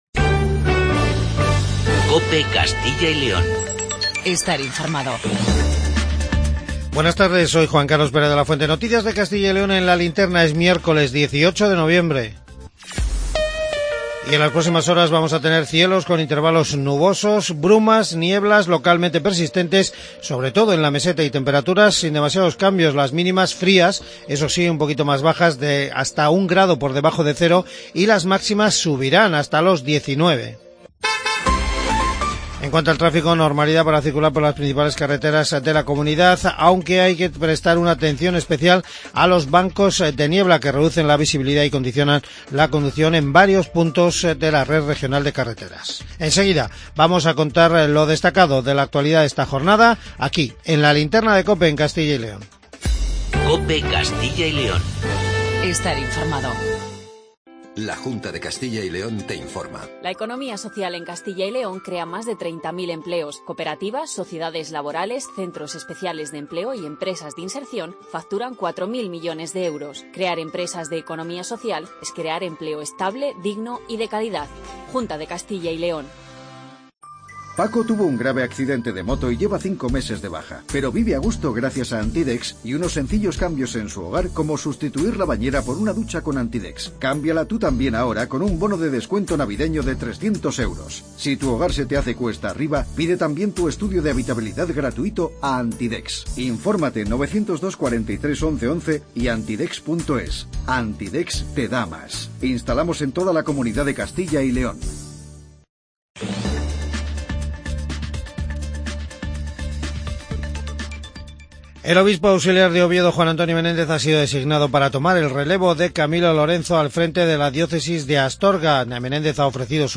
AUDIO: Informativo regional